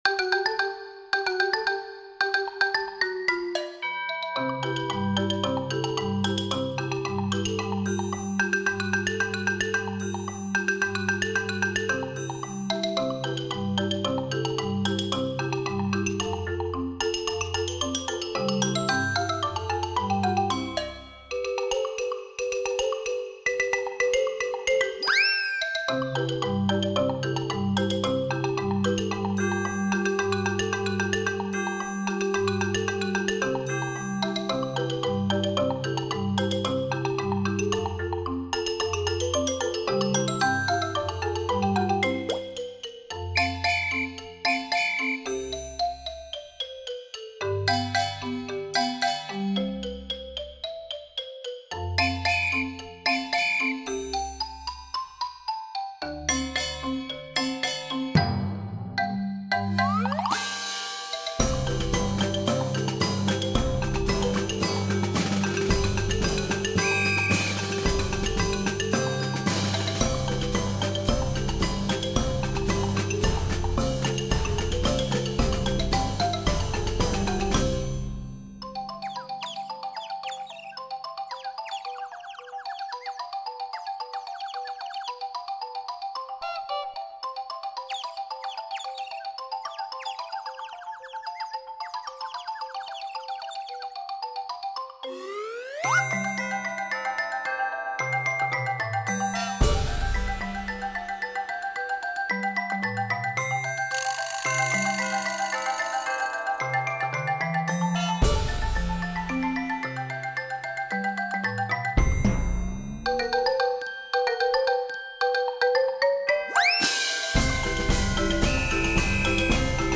Voicing: 3-13+ Percussion